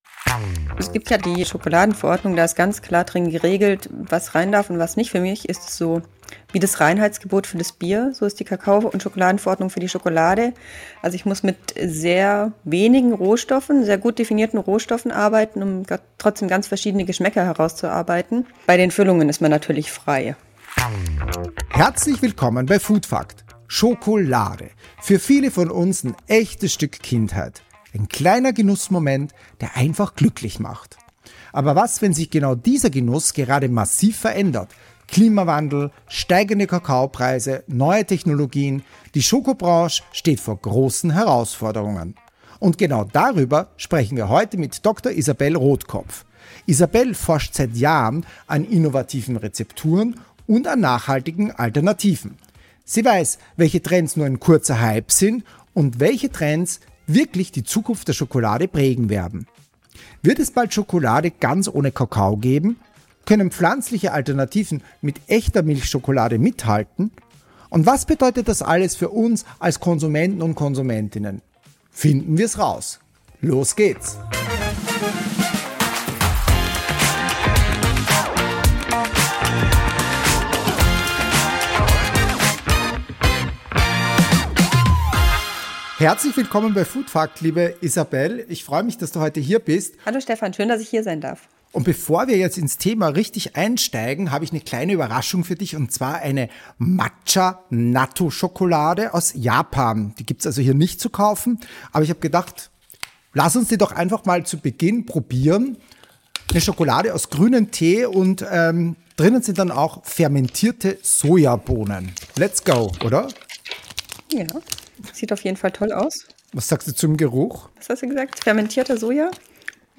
Die beiden sprechen darüber, ob es bald Schokolade ohne Kakao geben könnte und wie sich die Verbraucherpräferenzen ändern, insbesondere hin zu dunkler Schokolade und veganen Optionen und sie reflektieren dabei die Bedeutung von Zutaten und deren Einfluss auf den Geschmack.